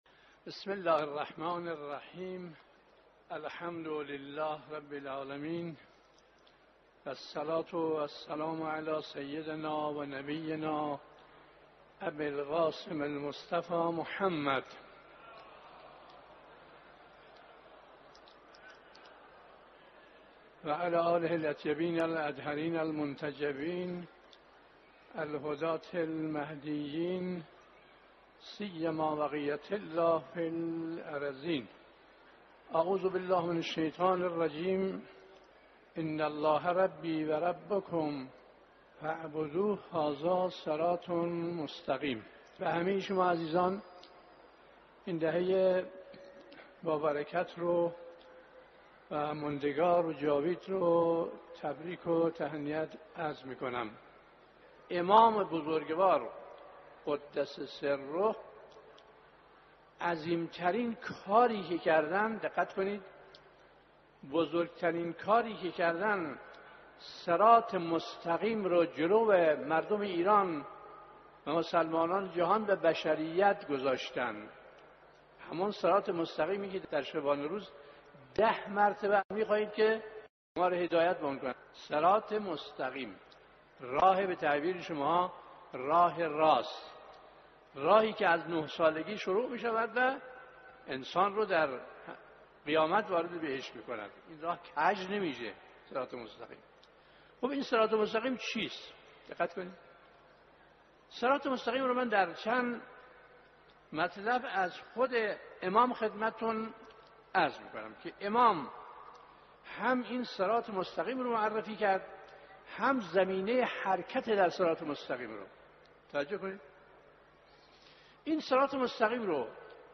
صوت سخنرانی مذهبی و اخلاقی صراط مستقیم یعنی راهی که ما را به بهشت می‌رساند و تنها اولیاء الهی هستند که می‌توانند این راه را به ما نشان دهند.